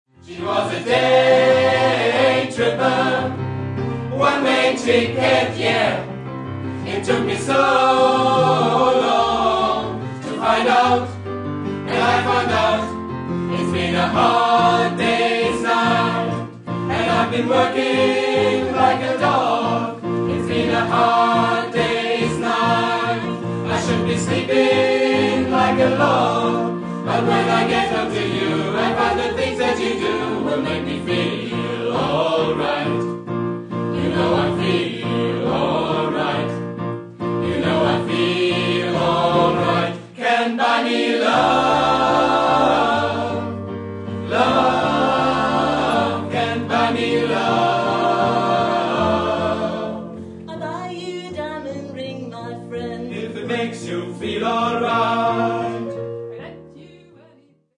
Junge Menschen singen von einer Zeit, von der sie keine Ahnung haben